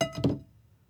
Food & Drink, Tableware, Plates, Picked Up And Put Down On Table SND4169.wav